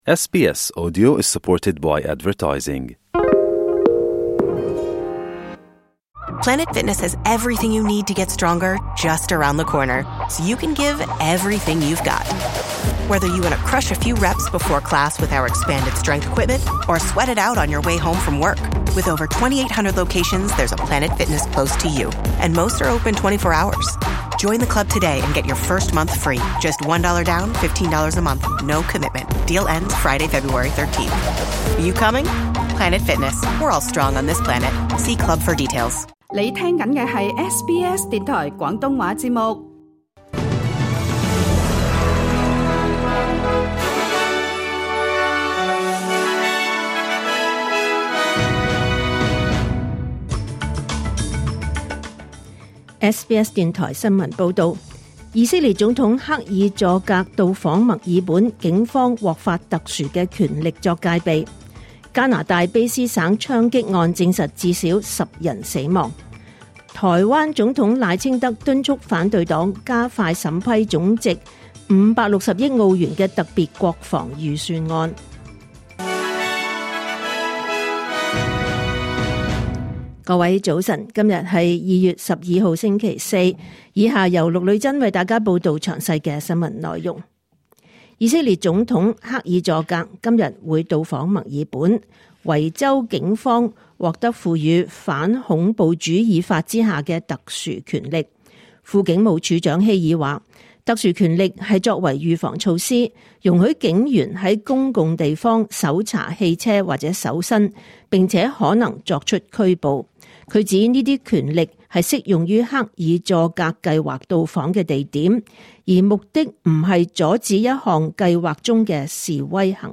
2026 年 2 月 12 日SBS廣東話節目九點半新聞報道。